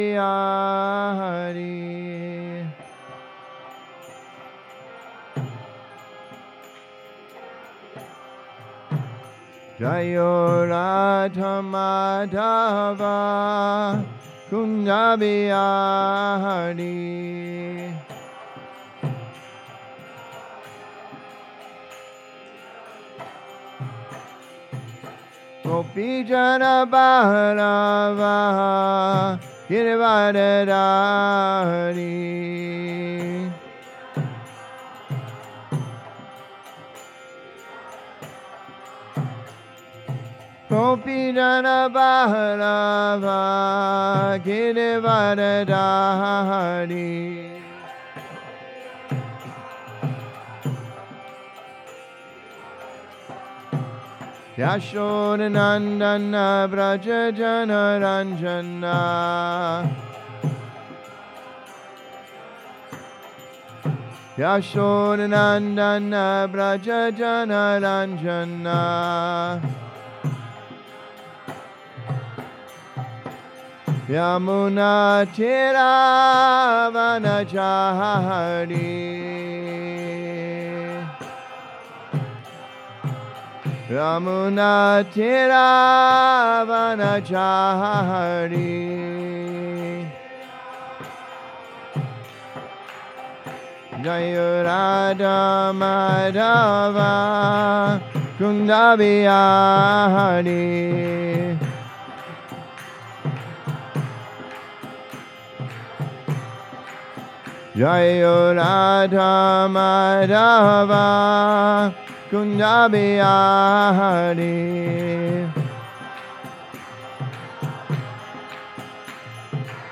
Sunday Feast Lecture
at the Hare Krishna Temple in Alachua, Florida